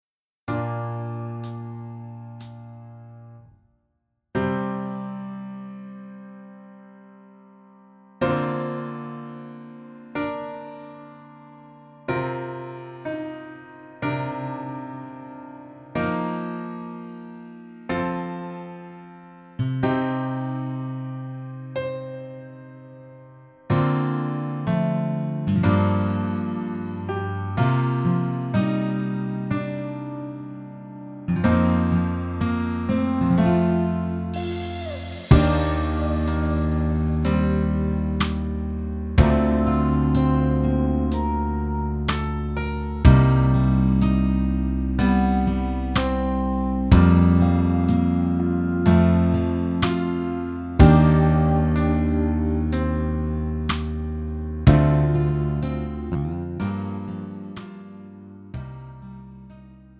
음정 -1키 4:06
장르 가요 구분 Pro MR
Pro MR은 공연, 축가, 전문 커버 등에 적합한 고음질 반주입니다.